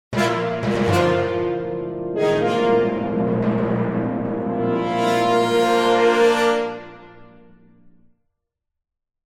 标签： 金属 命中 标题 喇叭 喇叭 冲击 电影 以来 拖车 效果 请求 电影
声道立体声